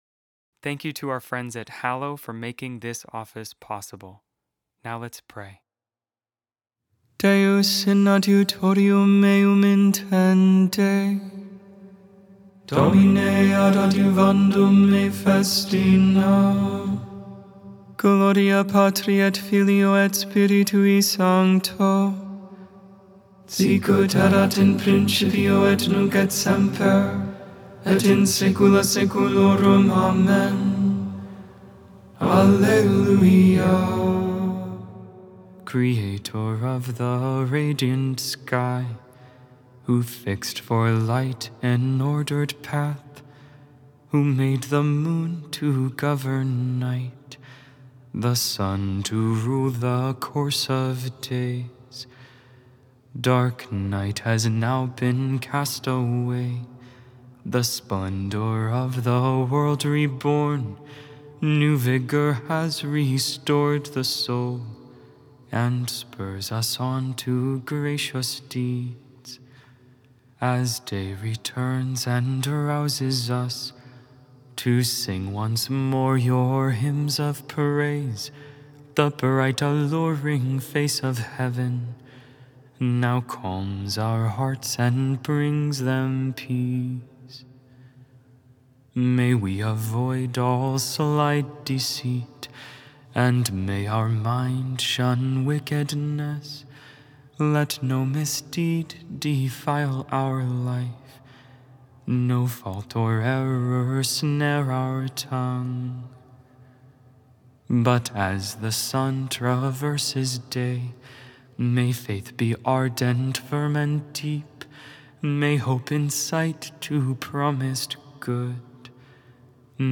Lauds, Morning Prayer for the 30th Wednesday in Ordinary Time, October 29, 2025.Made without AI. 100% human vocals, 100% real prayer.